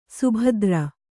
♪ subhadra